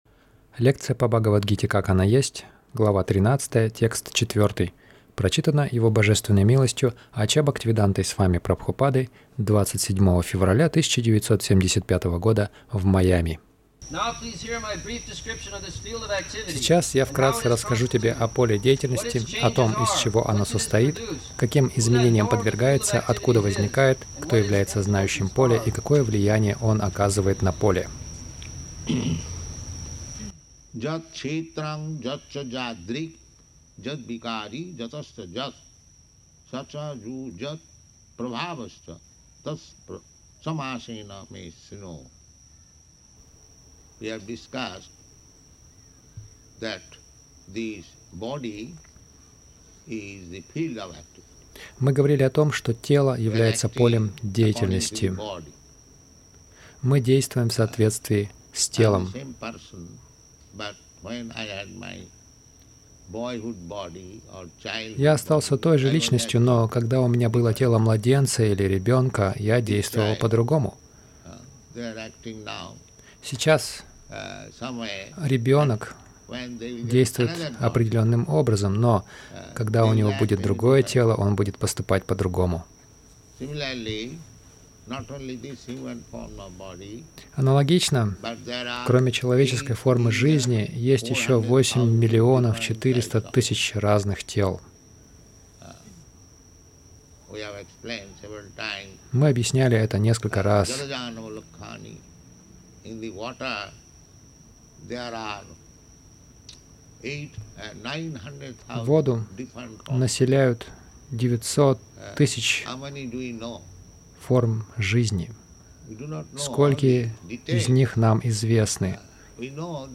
Милость Прабхупады Аудиолекции и книги 27.02.1975 Бхагавад Гита | Майами БГ 13.04 — Общайтесь с бхагаватами и ешьте прасад Загрузка...